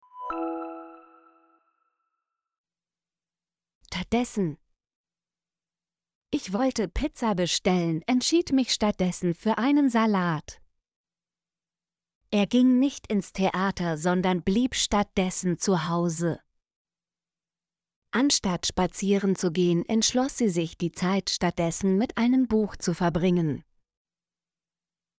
stattdessen - Example Sentences & Pronunciation, German Frequency List